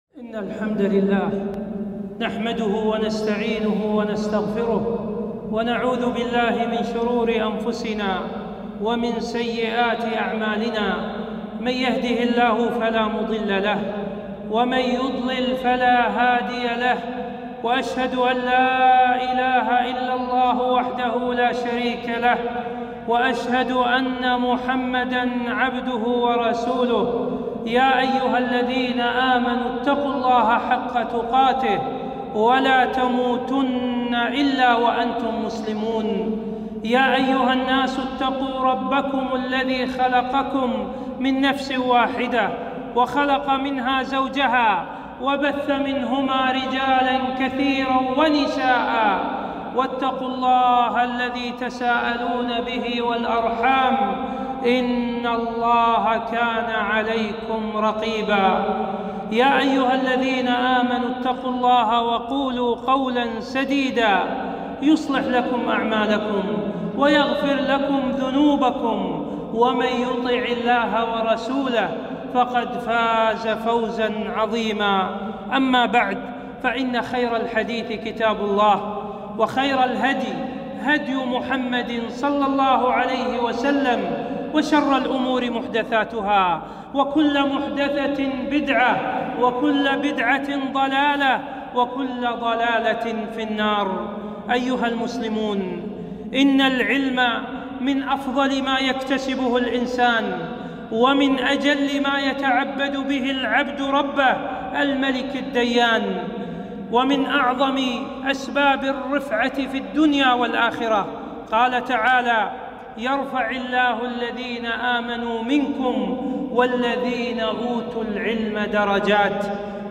خطبة - فضل طلب العلم